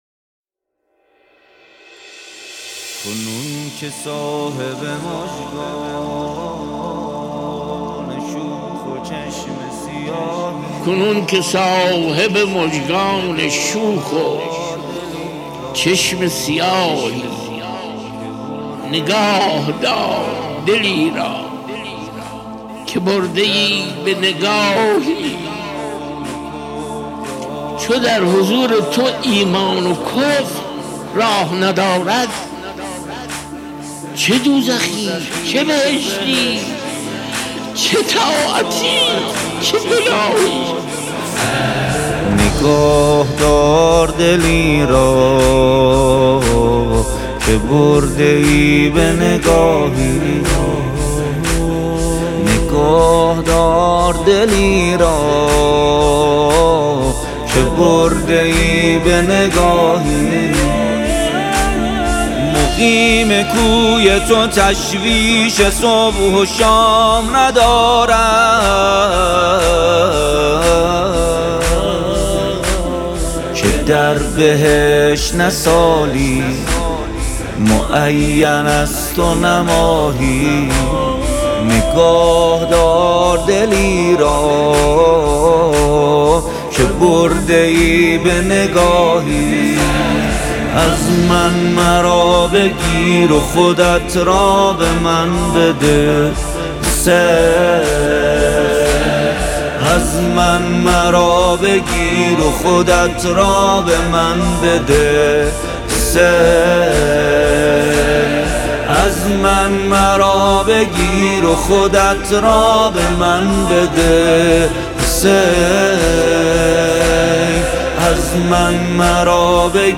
نوحه و مداحی